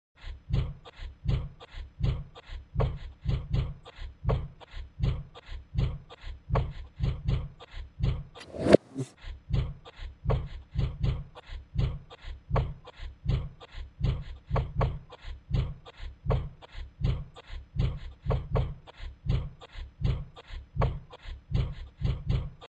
厨房用品 " 烤面包机杆
描述：烤箱杠杆 打开 杠杆向下 灯丝加热 杠杆向上 电磁铁冷却
Tag: 厨房 长丝 冷却 杠杆 filmanet 机械 电气 开关 设备 向下 向上 加热 烤面包机